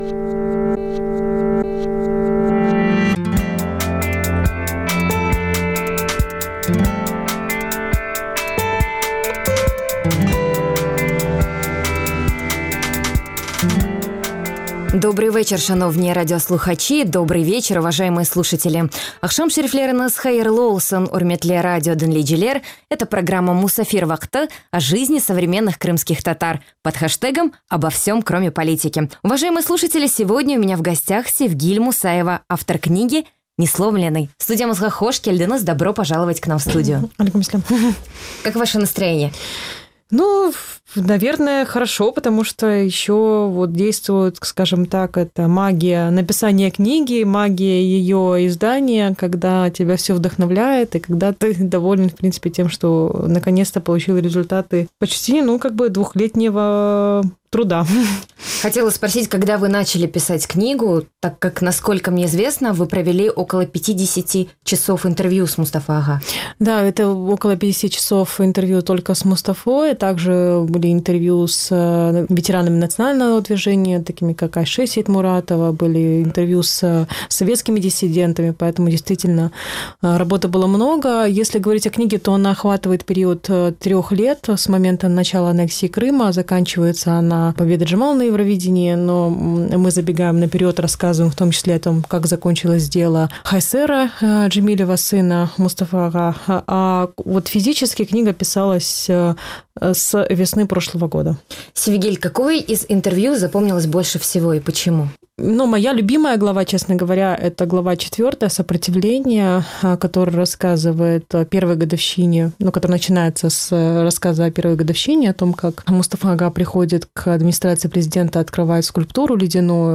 15 апреля в программе «Musafir vaqtı» говорят, о книге которую ждал не только крымскотатарский народ, но и весь мир. Об этом говорит наша гостья, автор книги – Севгиль Мусаева.